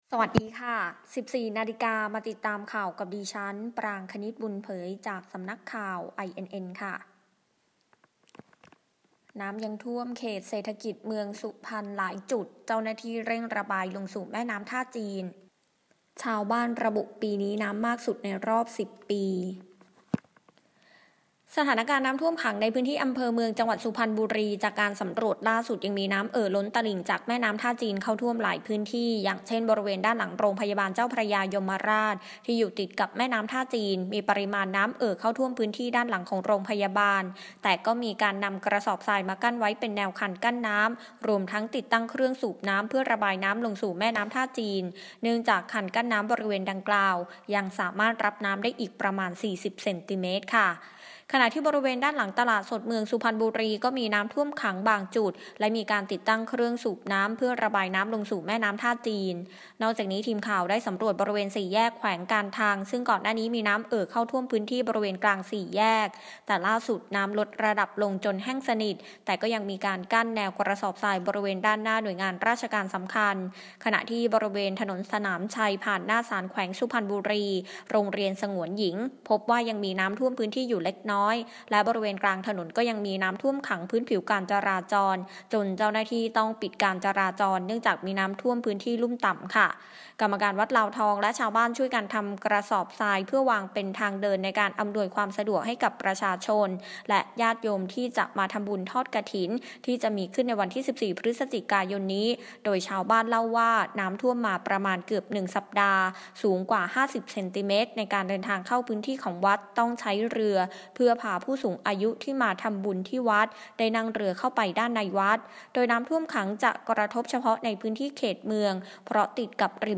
ข่าวต้นชั่วโมง 14.00 น.